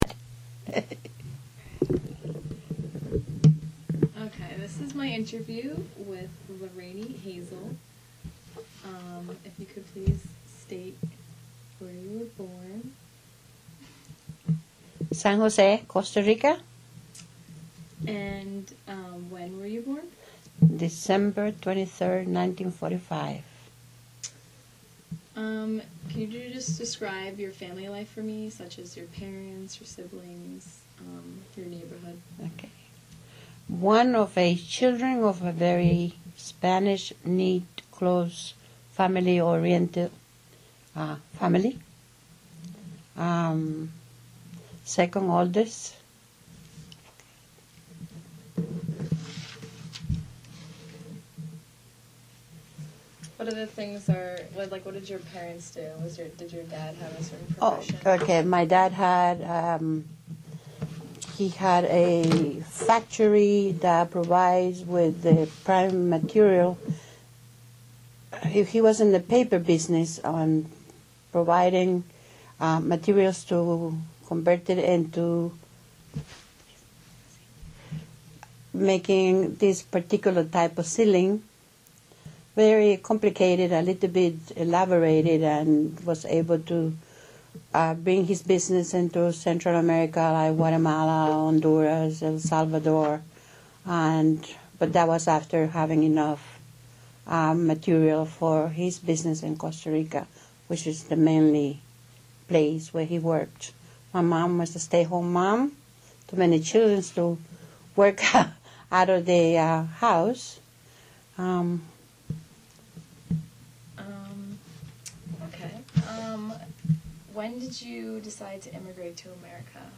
Source: Immigration and Memory Project Type: Oral History Collection